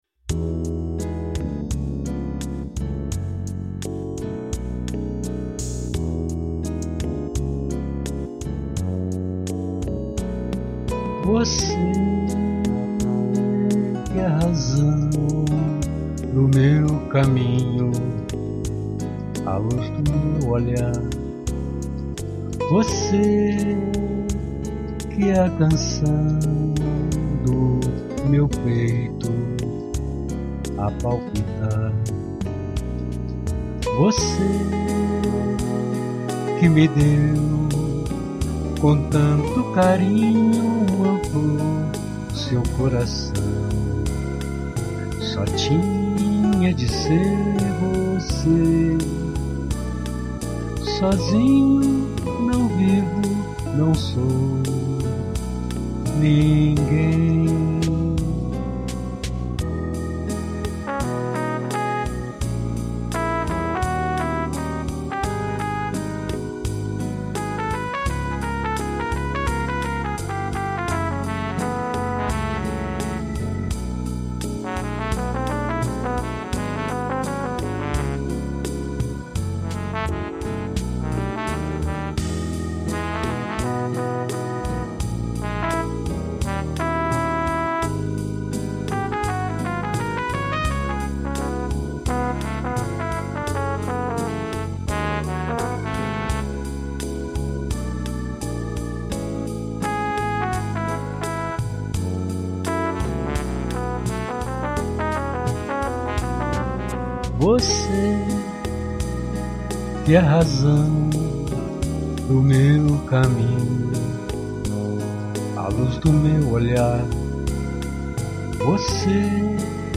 EstiloBossa Nova